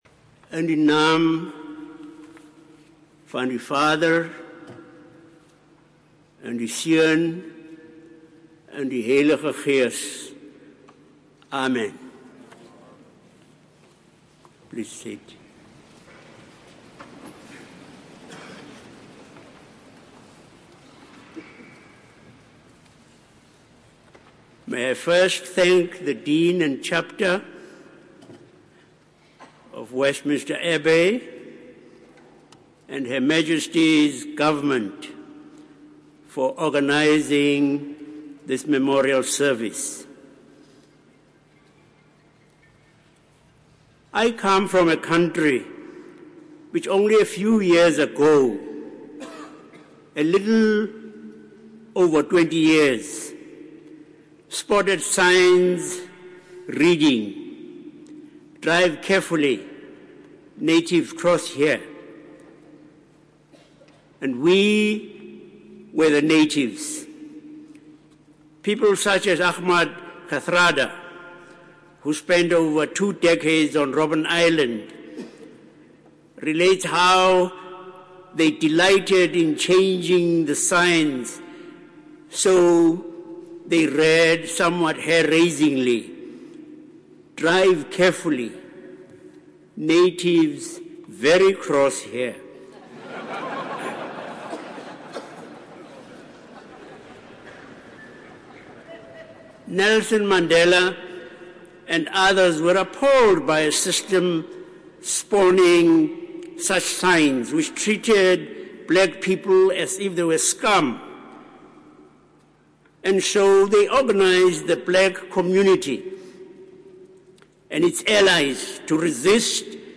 Desmond Tutu's Address given at a Service to Celebrate the Life and Work of Nelson Mandela
His Royal Highness Prince Henry of Wales represented Her Majesty The Queen at a Service to Celebrate the Life and Work of Nelson Mandela, former President of the Republic of South Africa, at Westminster Abbey on Monday 3rd March 2014. The service was also attended by the Prime Minister, the Right Honourable David Cameron MP, and the Deputy President of the Republic of South Africa, Kgalema Motlanthe.